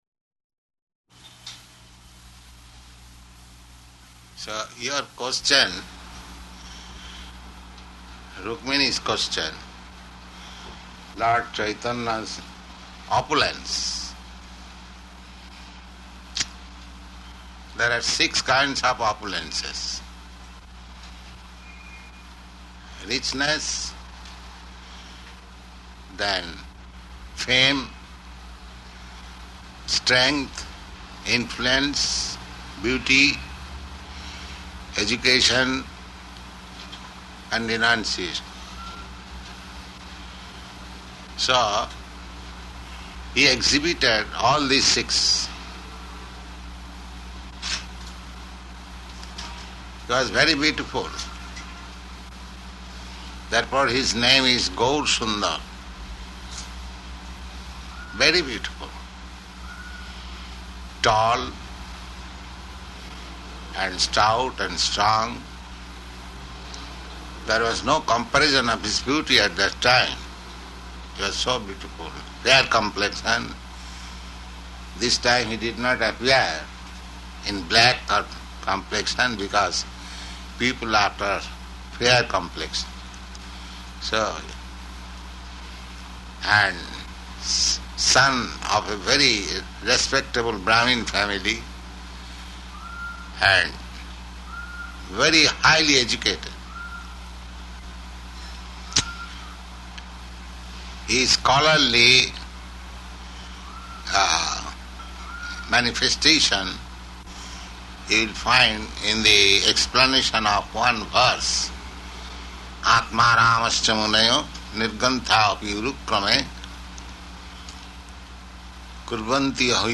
Questions and Answers